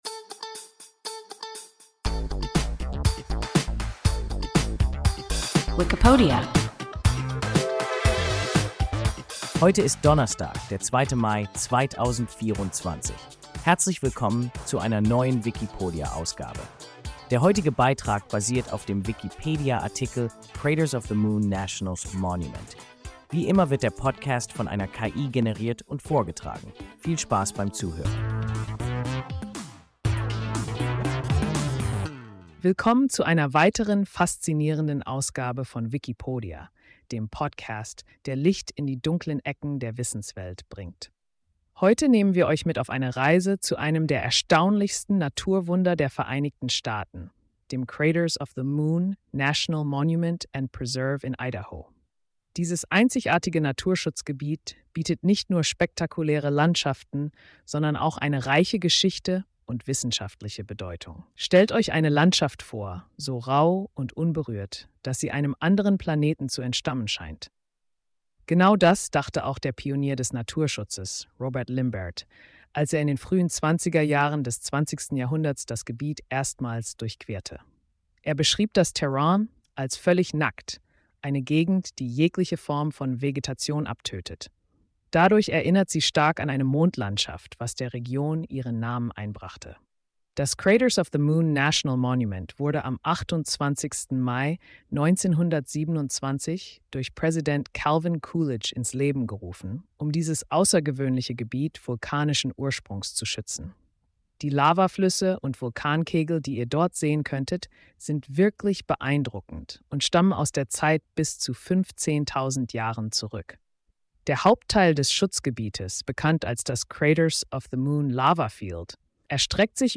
Craters of the Moon National Monument – WIKIPODIA – ein KI Podcast